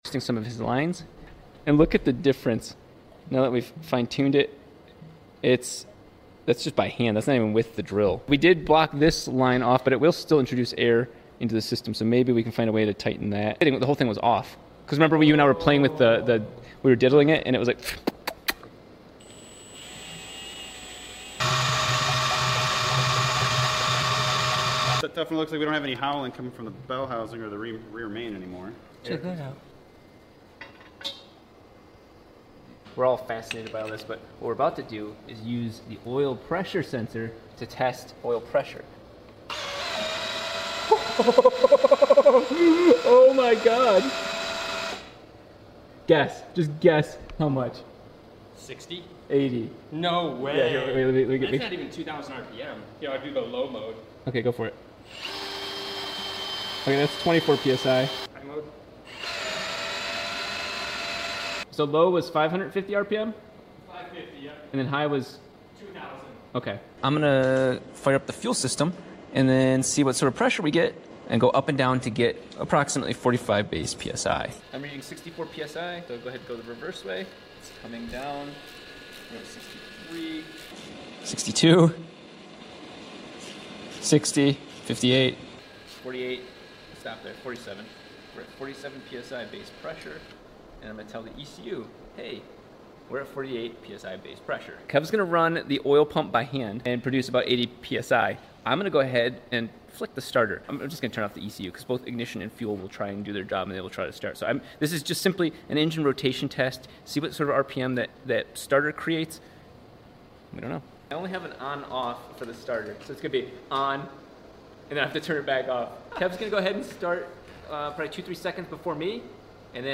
Part_4 Billet 4 Rotor Roars sound effects free download
Part_4 Billet 4 Rotor Roars to Life Unbelievable Sound